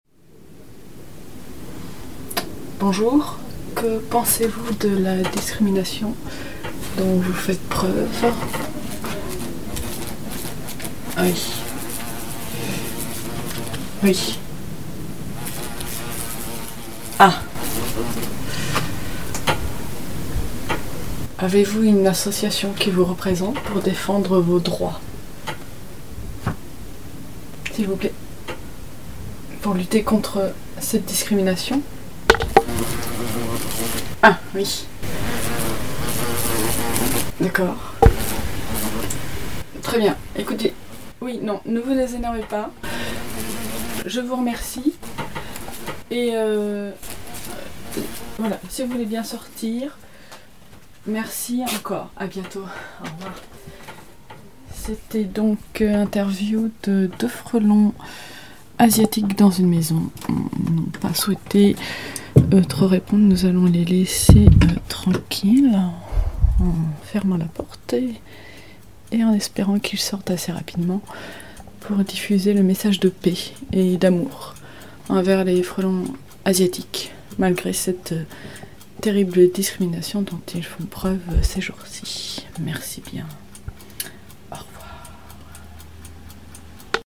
Sonorités entre reportages ou élucubrations sur-irréalistes.
Une courte interview de dernière minute en envoyé spéciale de deux frelons asiatiques victimes de discriminations en France